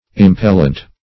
Impellent \Im*pel"lent\, a. [L. impellens, p. pr. of impellere.]